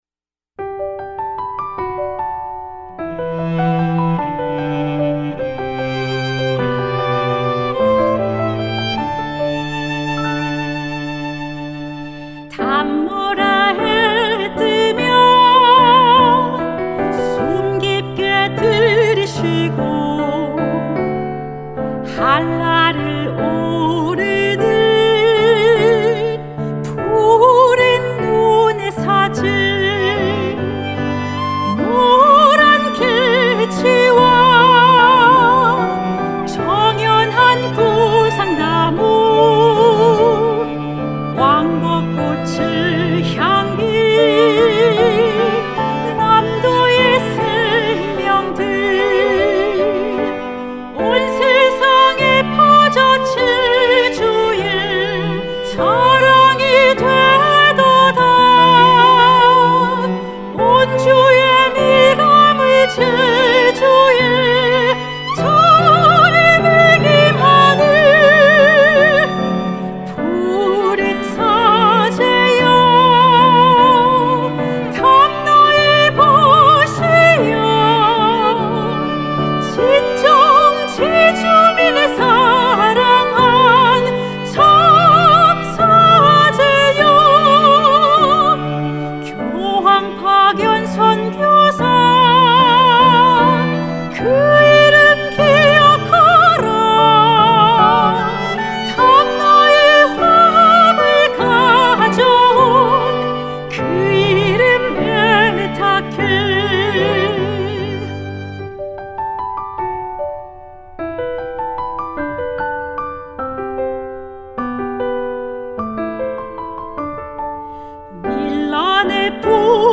맑고 청아한 고음과 섬세하고 화려한 기교를 지닌 소프라노
Cello
Violin
Piano